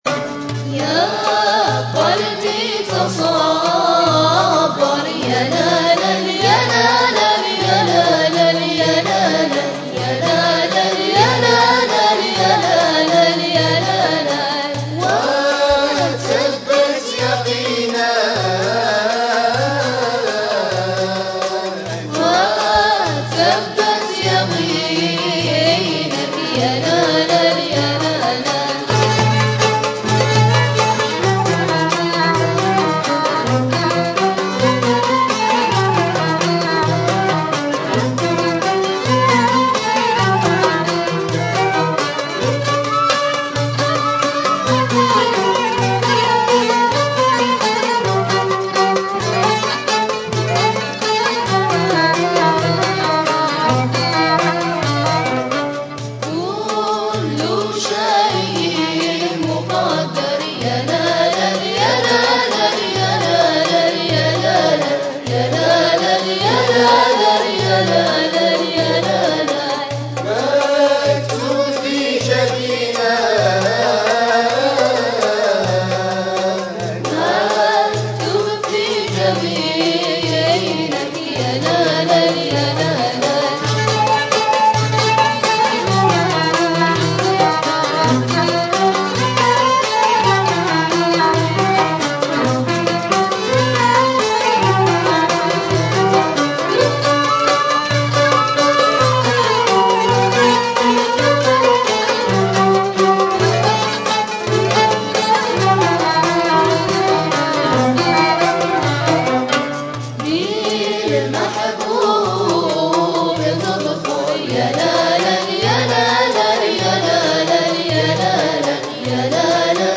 L'introduction à ce Derdj (en guise de Kursi) utilise le Djouab (réplique instrumentale) des ghessen.